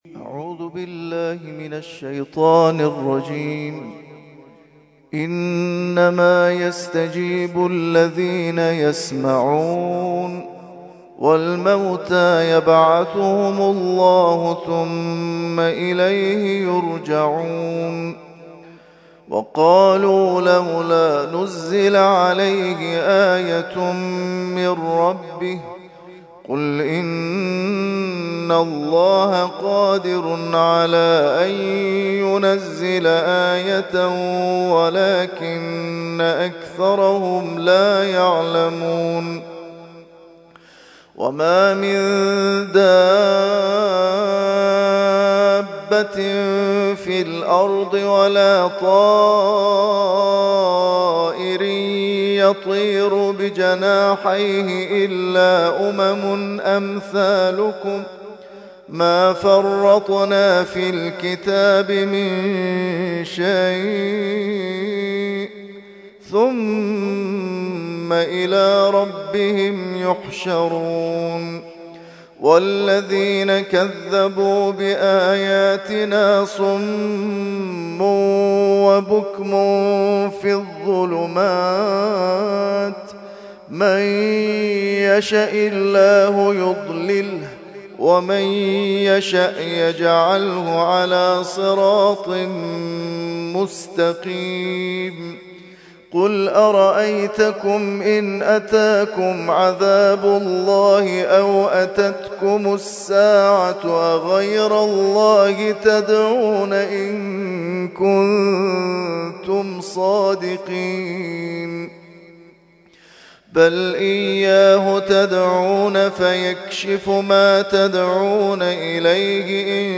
ترتیل خوانی جزء ۷ قرآن کریم در سال ۱۳۹۵